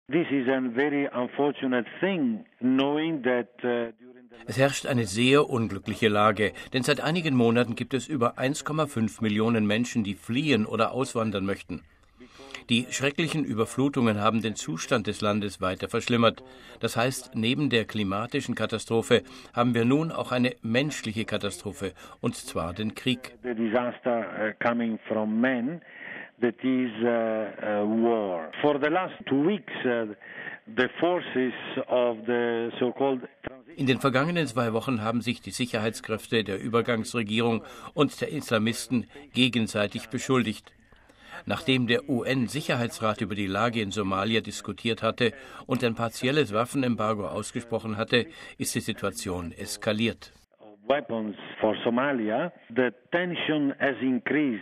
Wir haben mit dem Apostolischen Administrator in Mogadischu, Bischof Giorgio Bertin, über die gegenwärtige Situation gesprochen: